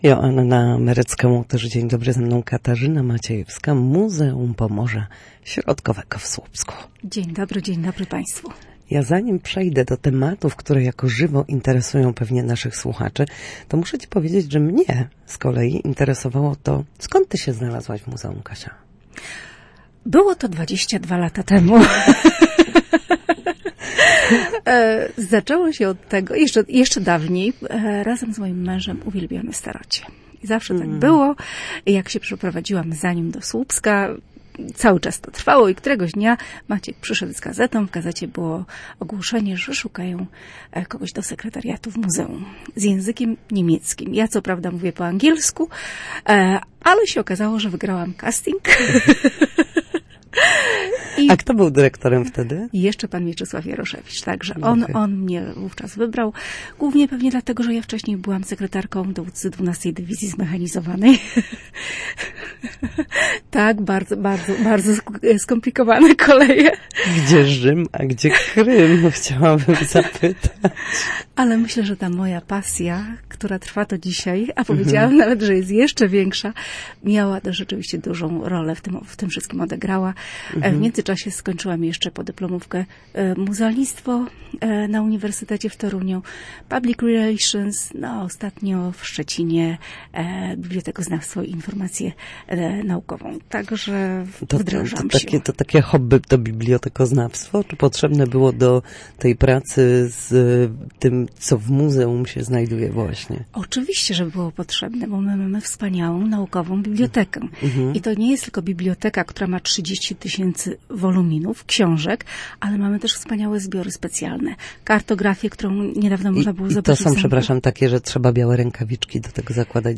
Od ponad dwóch dekad jest związana ze słupskim muzealnictwem. Rozmowa